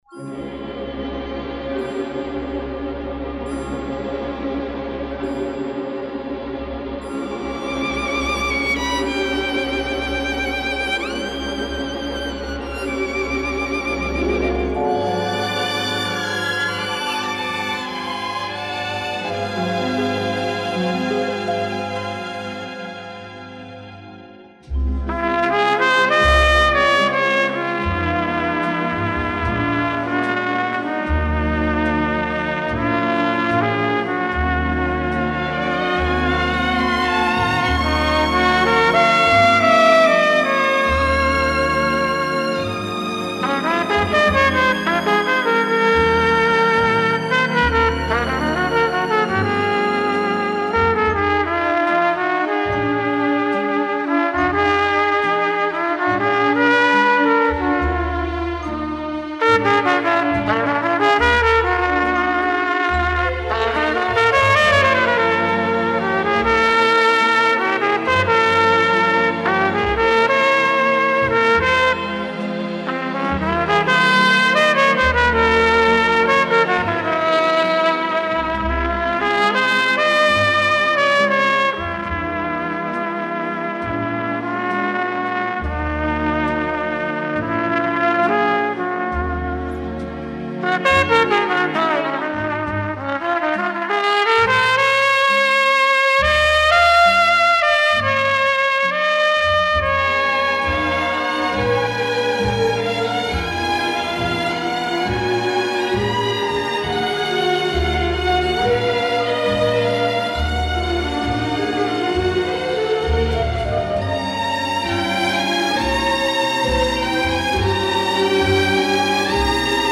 This album is schmaltz personified.